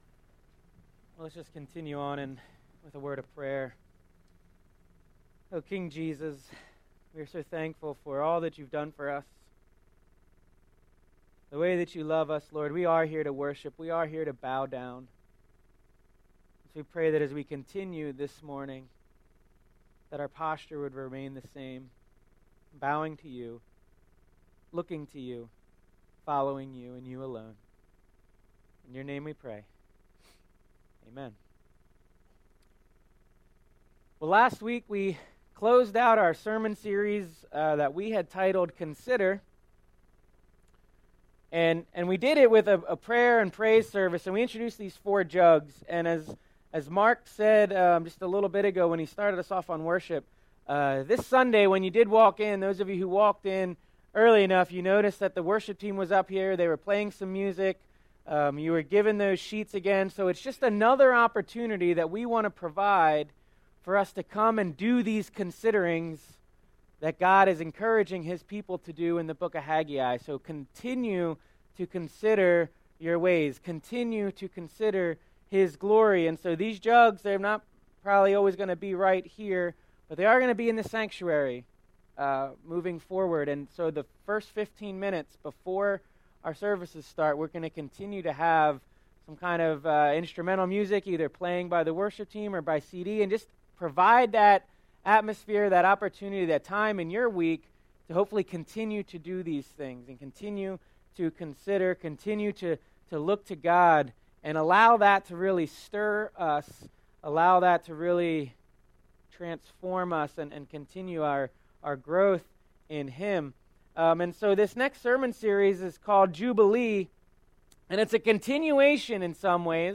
This sermon series examines Leviticus 25 and Jesus' proclamation in Luke 4.